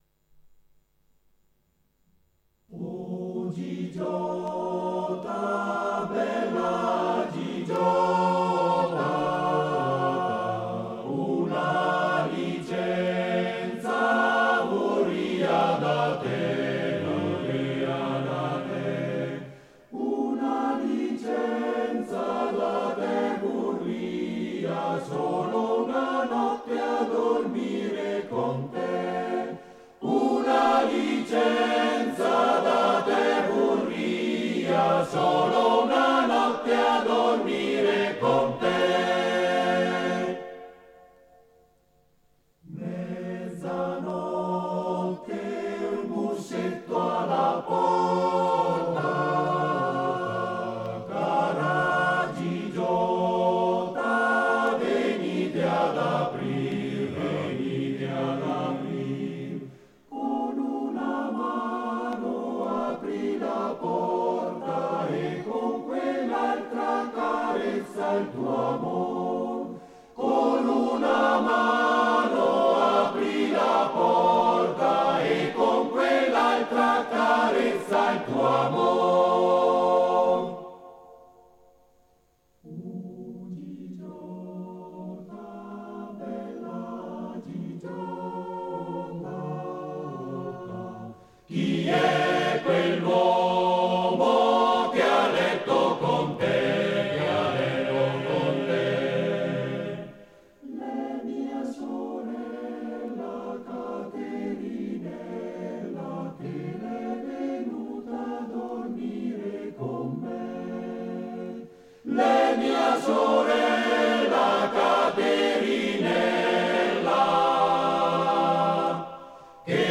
Arrangiatore: Mascagni, Andrea
Esecutore: Coro della SAT